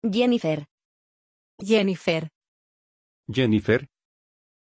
Aussprache von Jennifer
pronunciation-jennifer-es.mp3